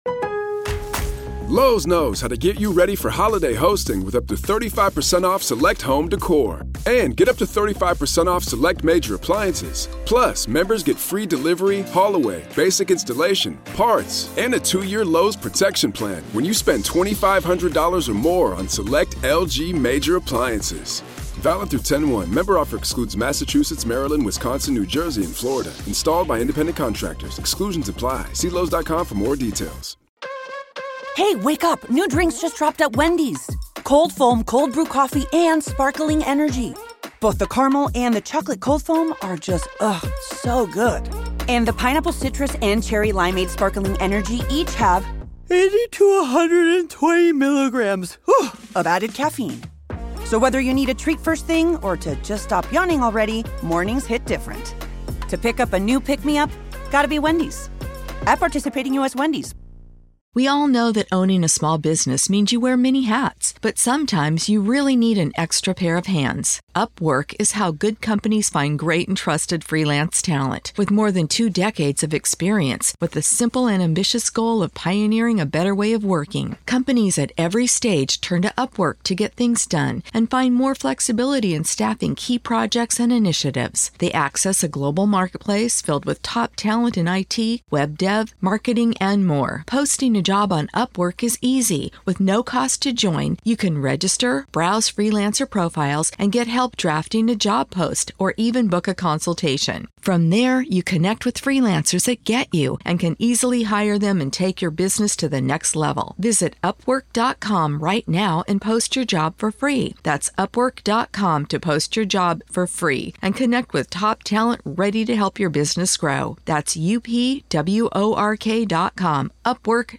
Commissioned by The Athletic to celebrate the return of fans to football stadiums, this immersive experience was recorded using binaural audio equipment.
You must listen via headphones to achieve the full effect, and quiet surroundings are recommended.